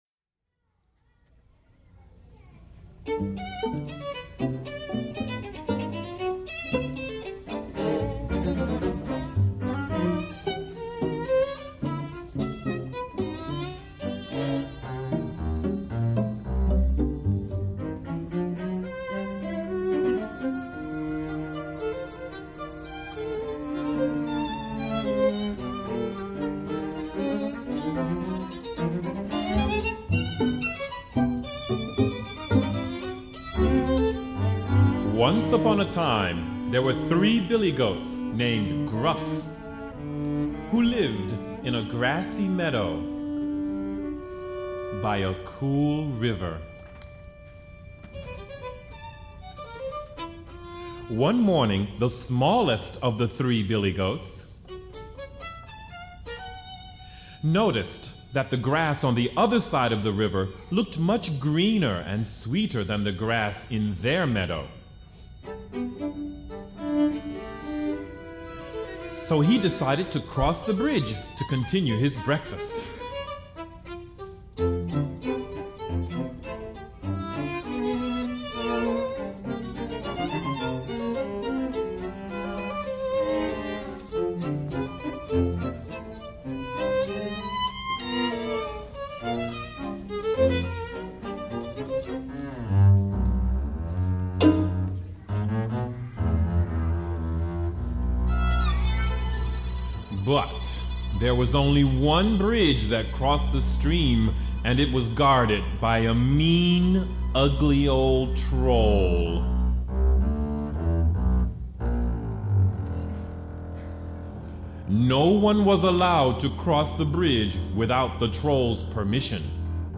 Voicing: String Quintet W